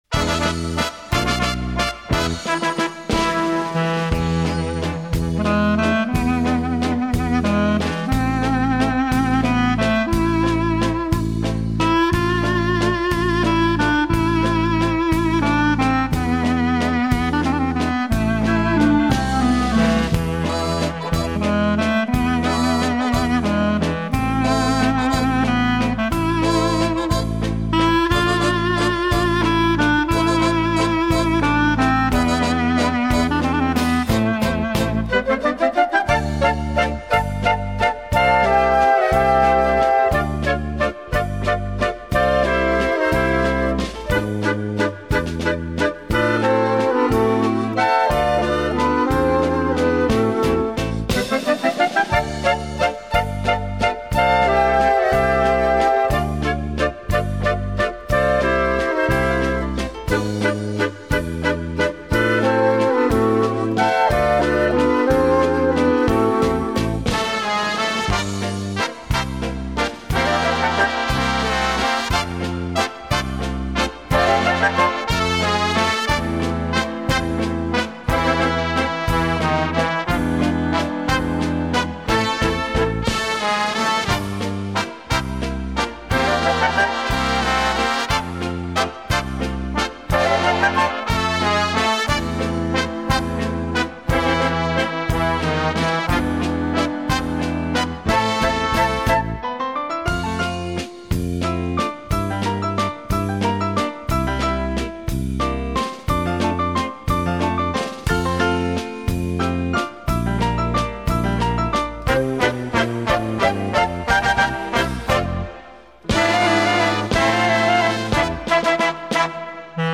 Вальс "Дунайские волны" Написан в 1898 г Iosif Ivanovici.